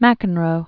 (măkən-rō), John Patrick, Jr. Born 1959.